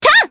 Aisha-Vox_Attack2_jp.wav